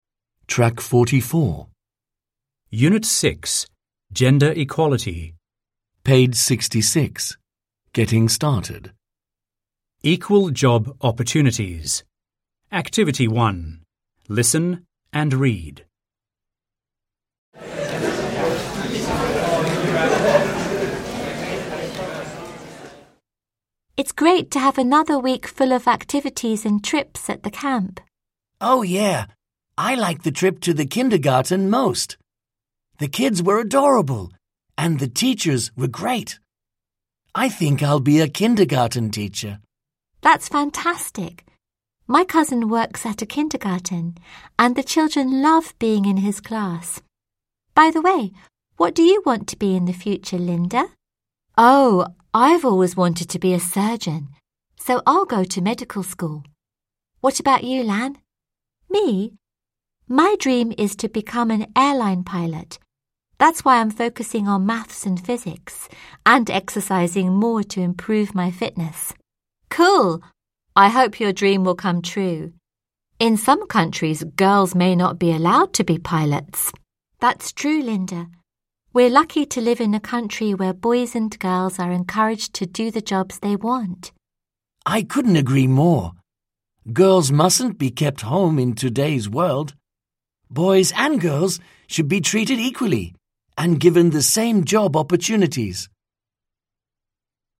At an international summer camp: Lan is talking with Mark and Linda about jobs.